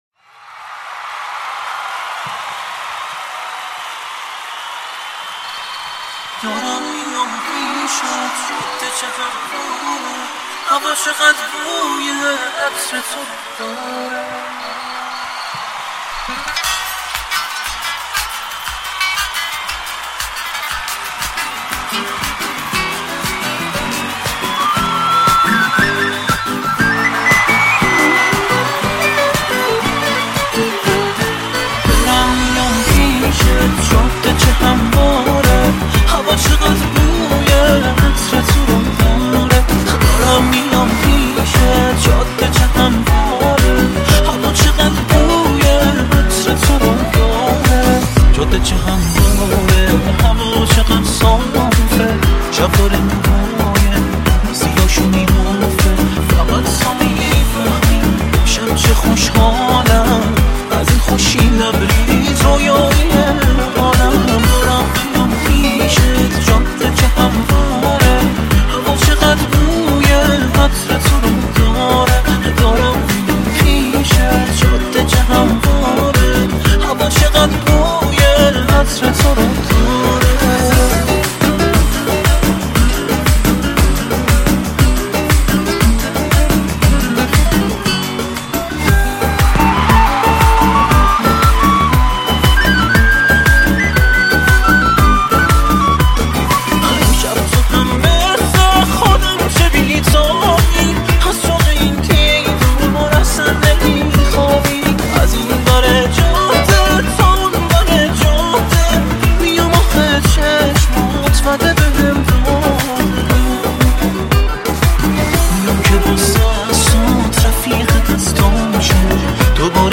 سبک این موزیک عاشقانه، شاد میباشد